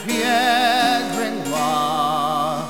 Hear this name said (in .wav format)